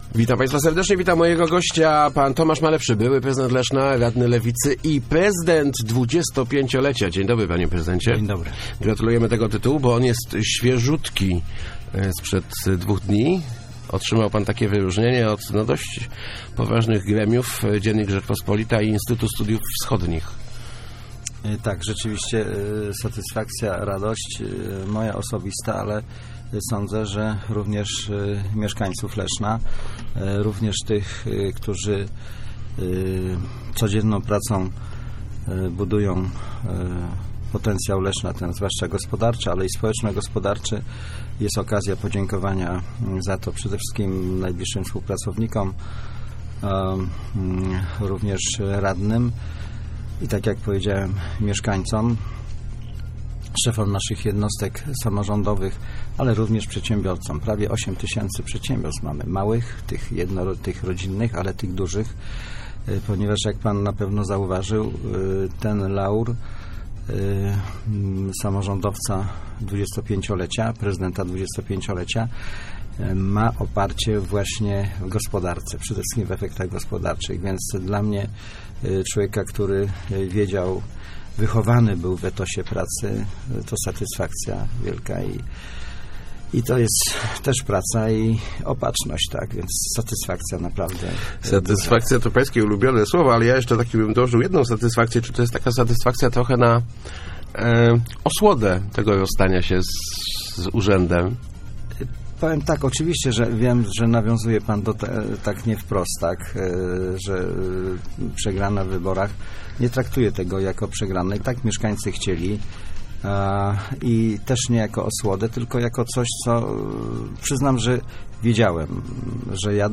Ten laur jest na swój swój sposób odpowiedzią na krytykę - mówił w Rozmowach Elki Tomasz Malepszy, były prezydent Leszna, uznany za Samorządowca 25-lecia.